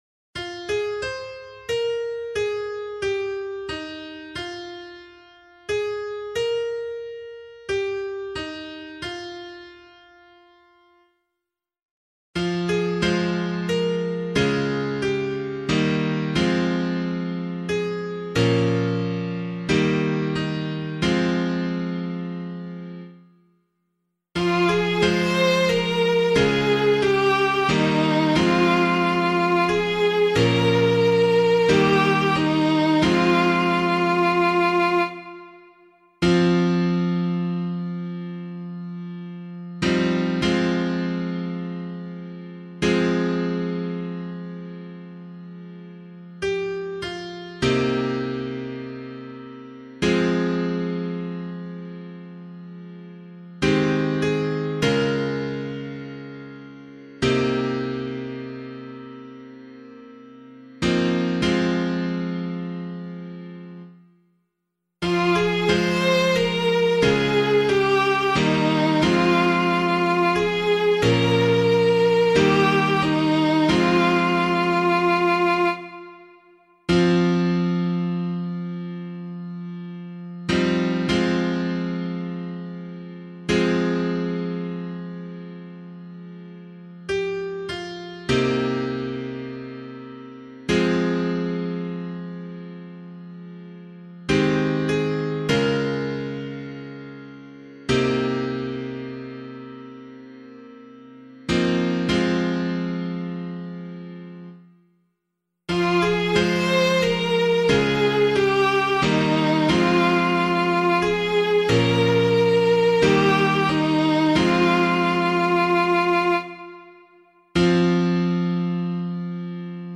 027 Easter 5 Psalm C [APC - LiturgyShare + Meinrad 2] - piano.mp3